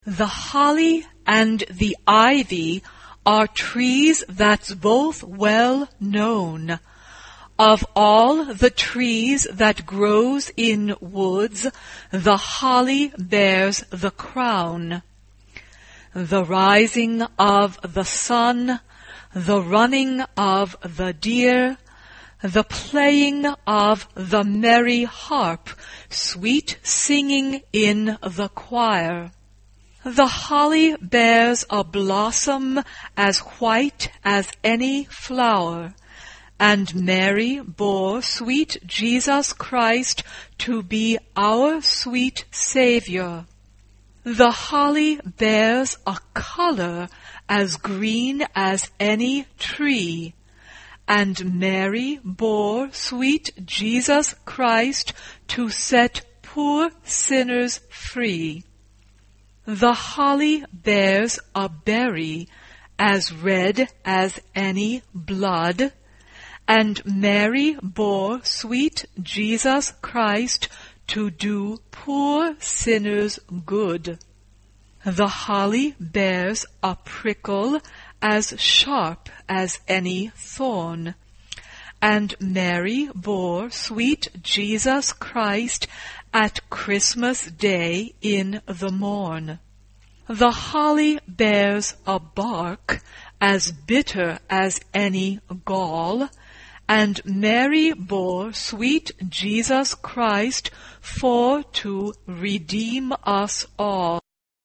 SA (2 voix égale(s) d'enfants) ; Partition complète.
Chant de Noël. Carol.
Instrumentation : Piano (1 partie(s) instrumentale(s))
Tonalité : fa majeur